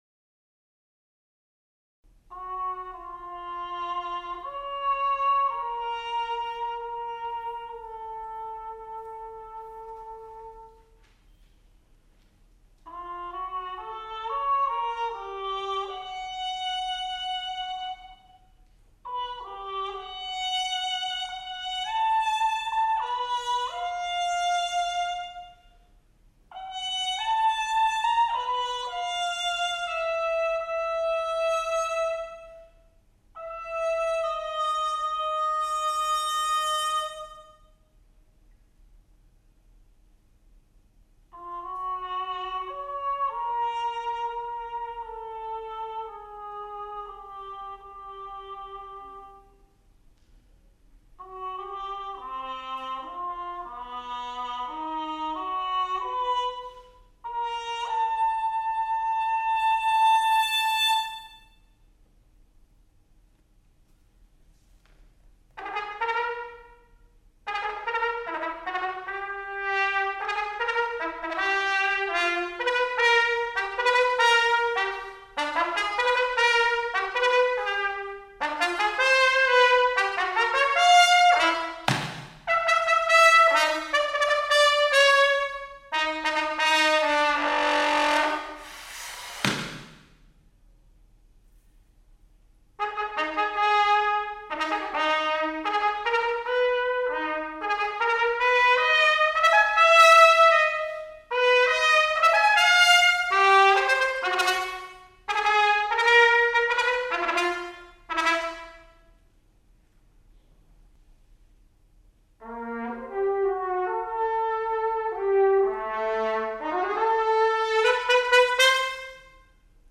For Solo Trumpet
Unaccompanied.